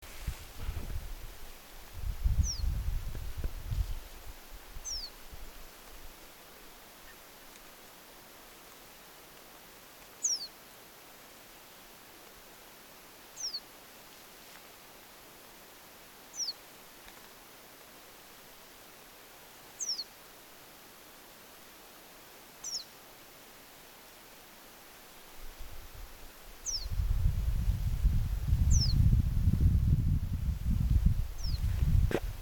A yellow wagtail (Motacilla flava) has been following me.
The singing has become more intense as it has closed in on me. I hear the characteristic sharp, drawn out and high-pitched tune falling at the end – transcribed as “tsiiv, tsiiv”.
It has two or three notes resembling the contact in timbre. To me the sound is clear, clean and aesthetical beautiful.